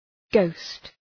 Shkrimi fonetik {gəʋst}